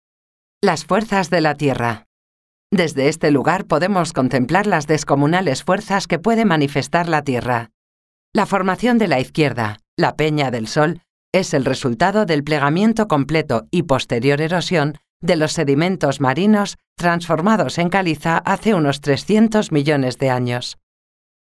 Locucion: